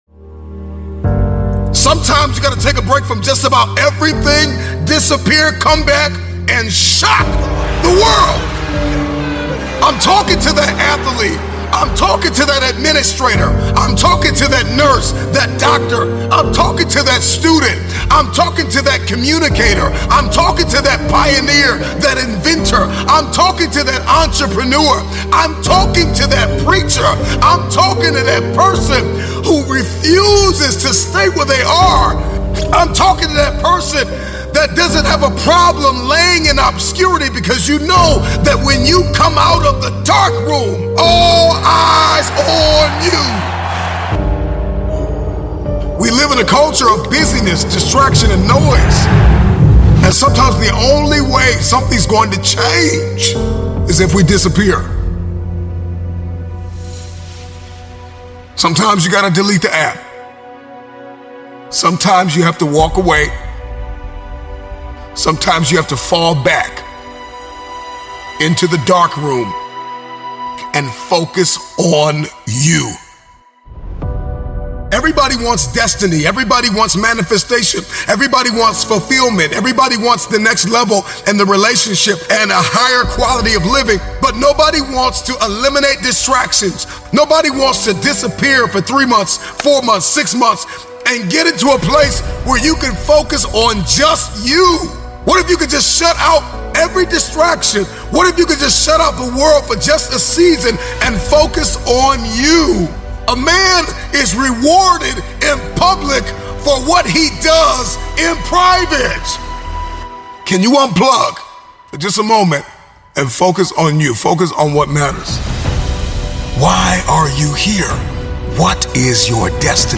one of the Best Motivational Speeches Ever